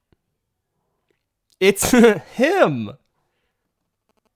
English_Emotional_Speech_Data_by_Microphone